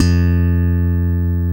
Index of /90_sSampleCDs/Roland L-CD701/BS _Funk Bass/BS _5str v_s